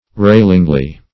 railingly - definition of railingly - synonyms, pronunciation, spelling from Free Dictionary Search Result for " railingly" : The Collaborative International Dictionary of English v.0.48: Railingly \Rail"ing*ly\, adv. With scoffing or insulting language.